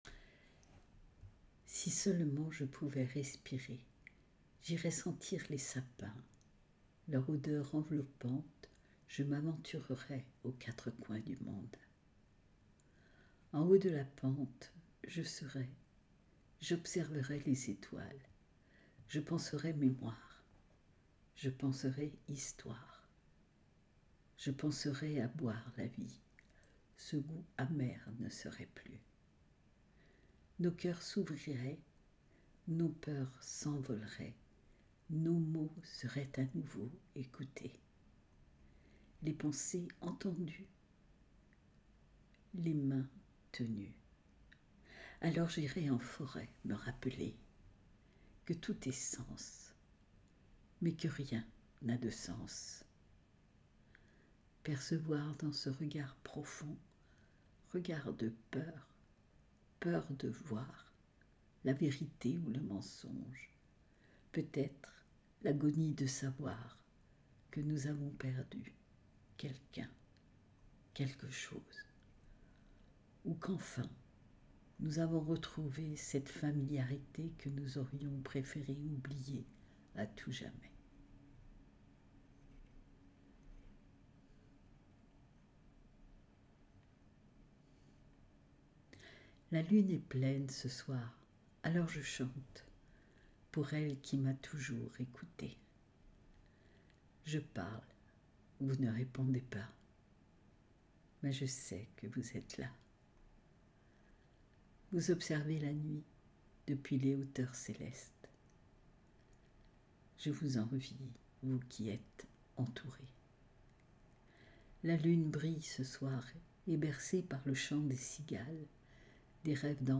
Quel plaisir j’ai eu à mettre ma voix sur ses mots.